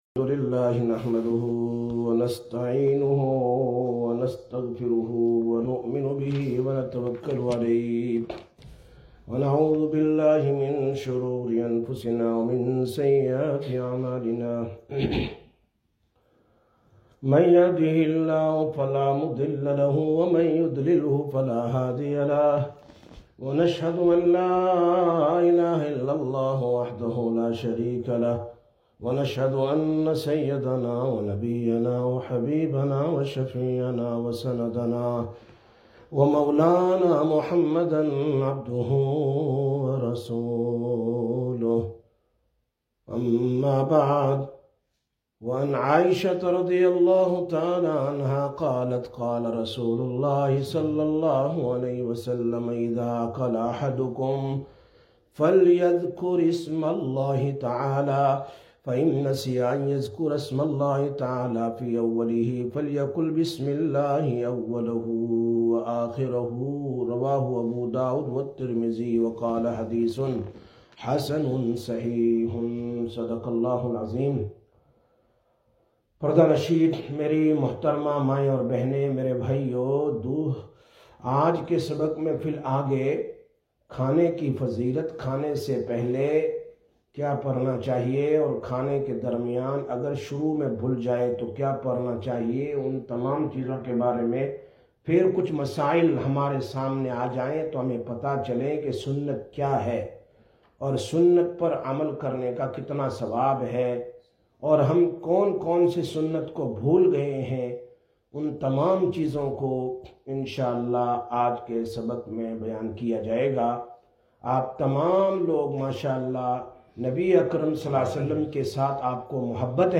16/03/2022 Sisters Bayan, Masjid Quba